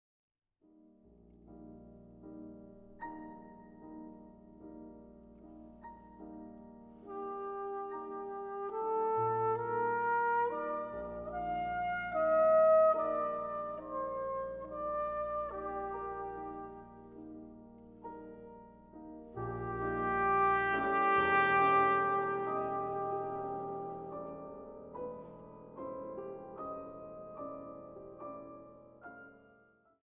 Piano
For Trumpet in C and Piano